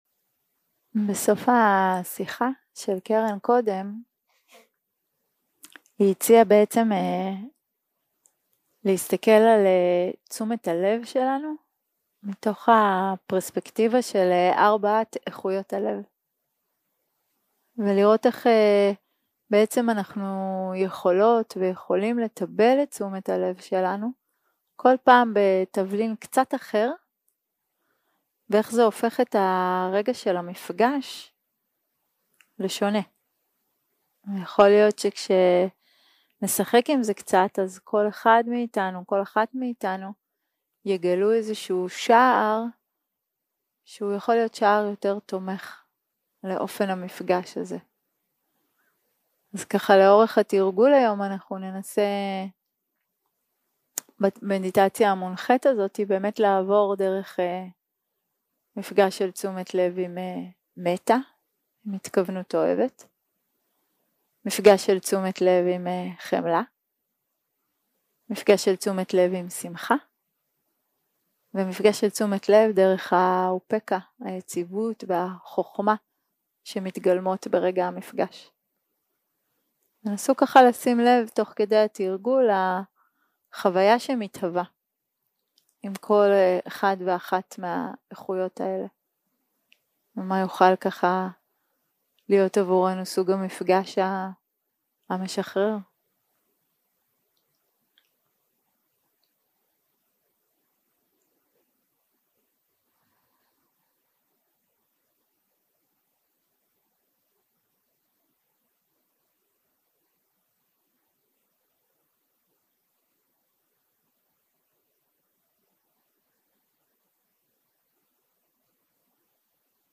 יום 2 - הקלטה 3 - ערב - מדיטציה מונחית - תשומת הלב דרך איכויות הלב
סוג ההקלטה: מדיטציה מונחית שפת ההקלטה